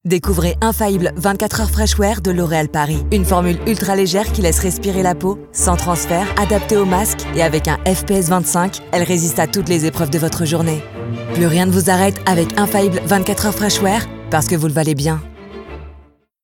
Franse voice-over
Natuurlijk, Opvallend, Veelzijdig, Vertrouwd, Warm
Telefonie